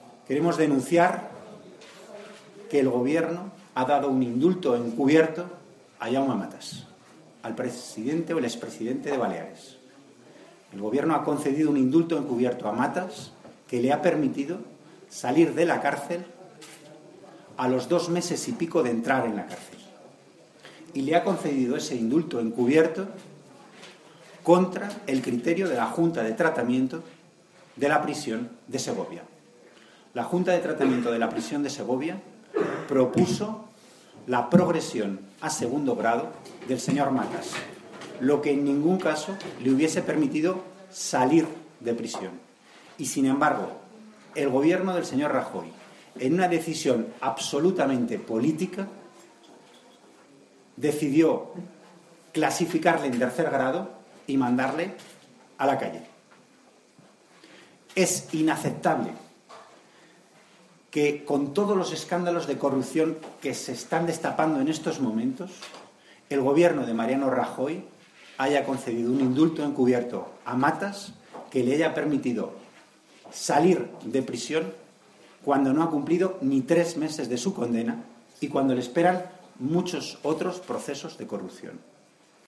Declaraciones de Antonio Hernando sobre el "indulto encubierto" que le ha concedido el Gobierno a Jaume Matas 3/11/2014